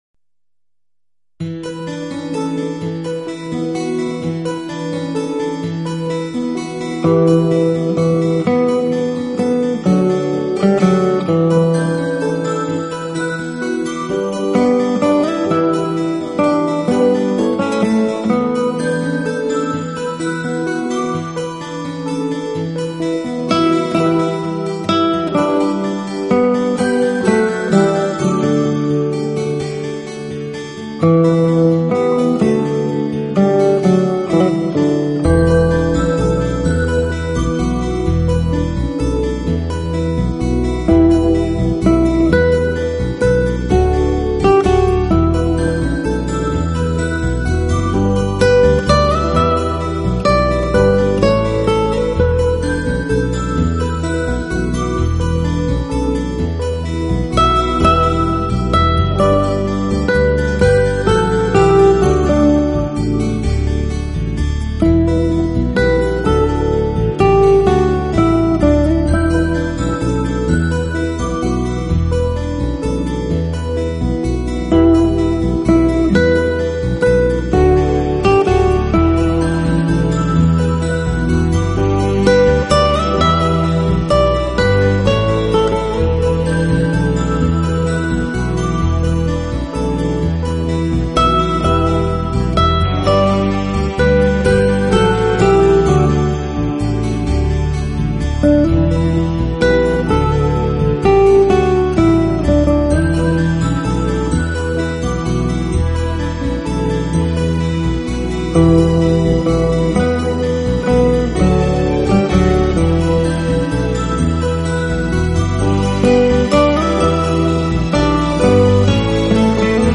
专辑音色清脆动人且温馨旖丽，不禁展示了精彩绝伦的空间感，而且带出吉他音箱共鸣
浪漫吉他曲，经典西洋乐，音符似跳动的精灵，释放沉睡已久的浪漫情怀，用吉他的清